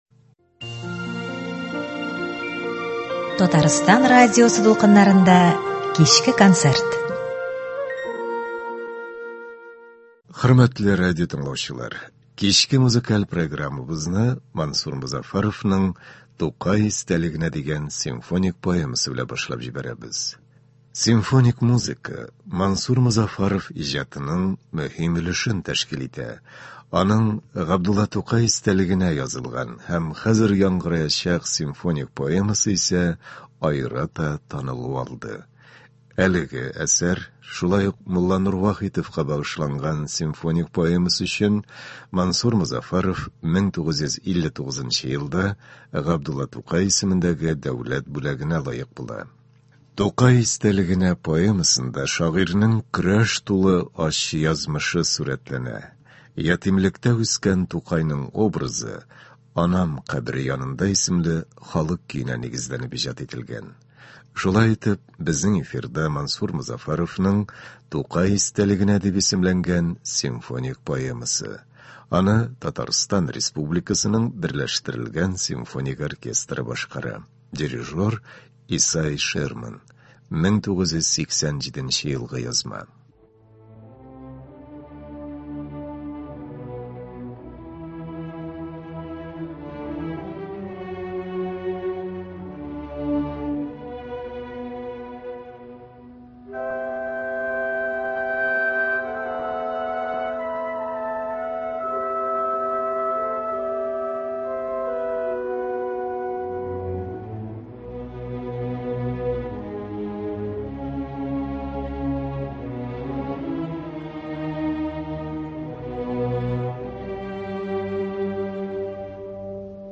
Симфоник поэма.